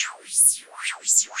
RI_RhythNoise_170-02.wav